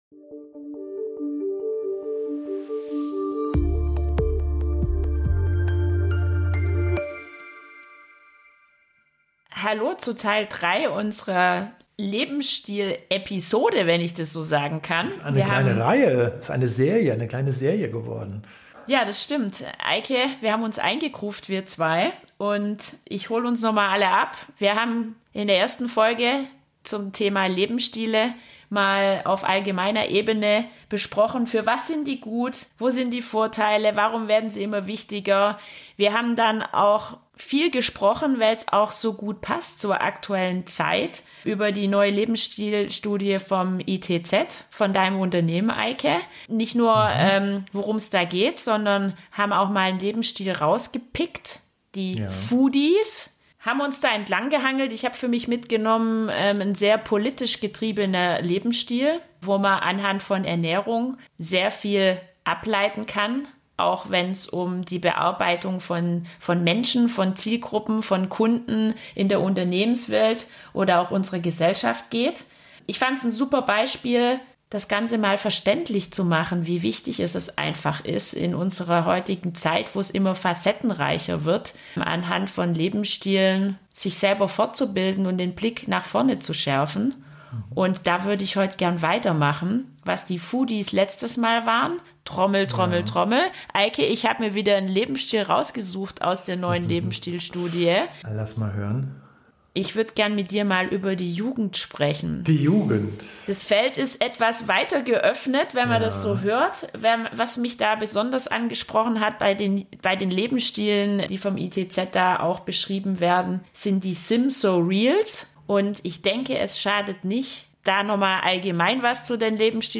Im Gespräch der beiden Zukunftsforscher:innen wird deutlich: "SIM-so-reals", "Boomerang Kids" und die "Generation Lost" suchen nach jeweils sehr eigenen, krisenadaptierten Wegen der Identitätsbildung.